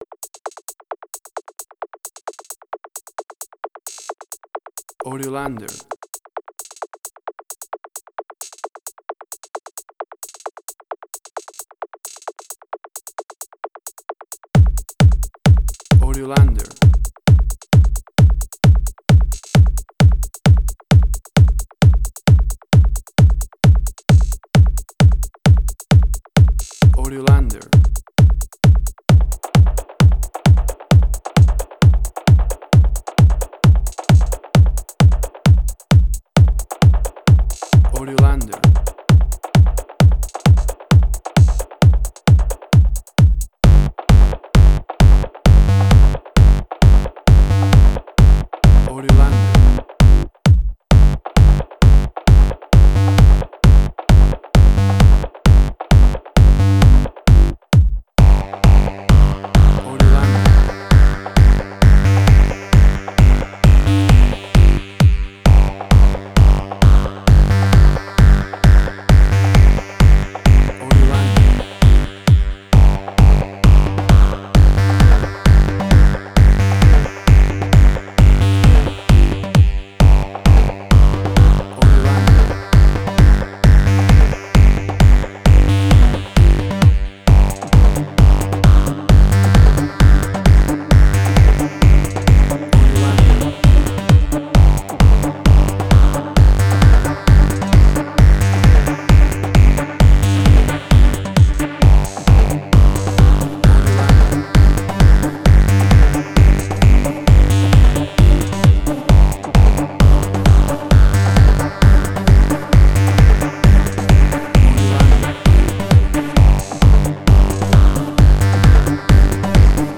House.
Tempo (BPM): 132